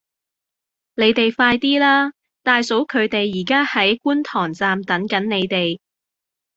Голоса - Гонконгский 90